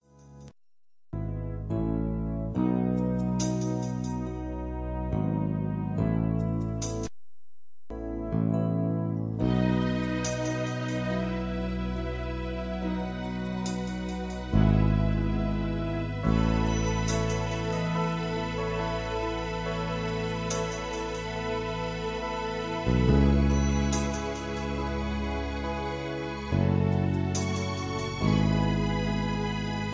(Key-F-Eb) Karaoke MP3 Backing Tracks